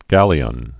(gălē-ən, gălyən)